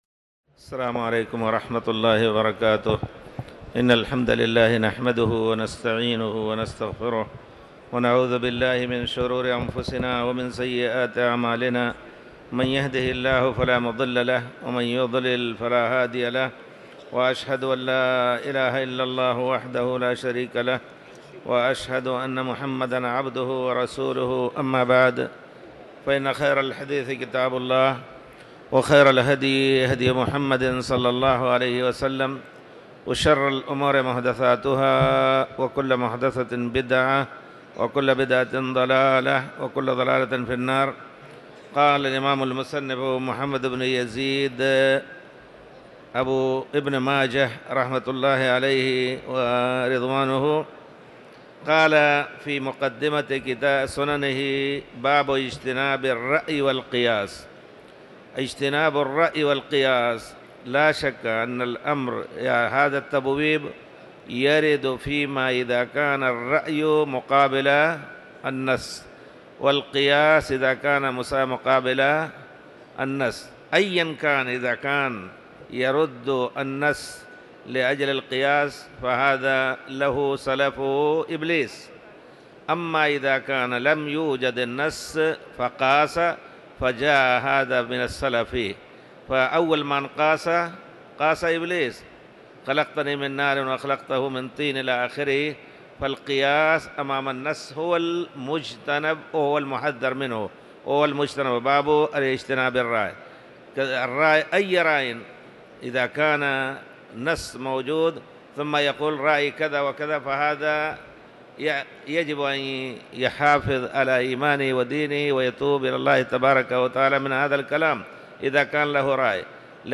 تاريخ النشر ١٨ رمضان ١٤٤٠ هـ المكان: المسجد الحرام الشيخ